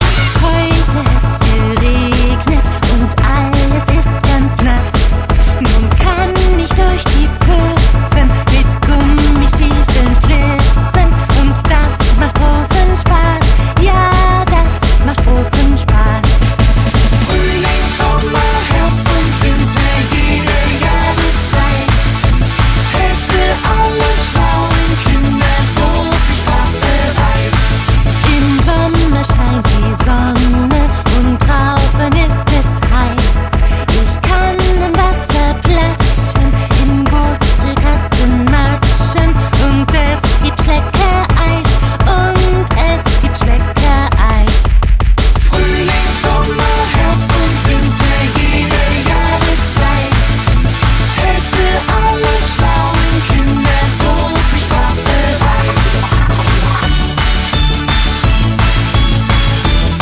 • Sachgebiet: mp3 Kindermusik